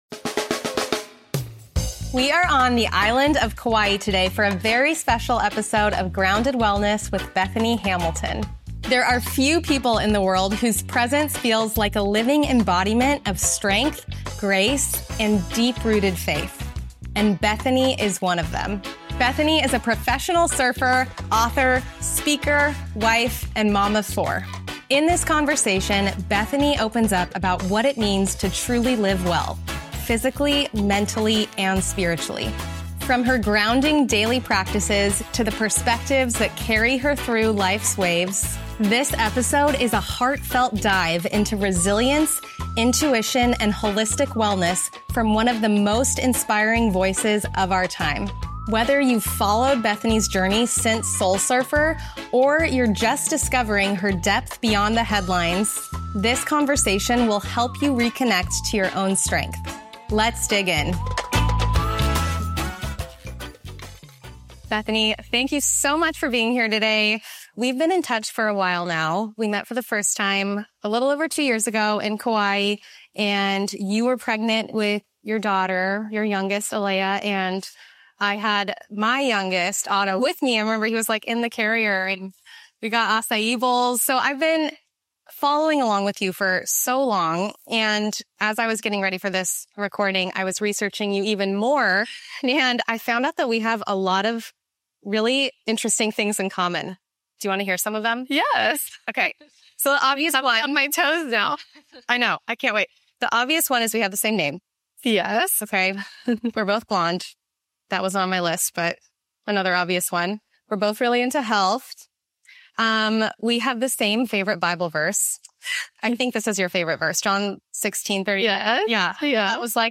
If you’ve been stuck in survival mode, trying to do it all while feeling like you're falling apart — this conversation is for you.